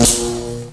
stunstick_impact1.wav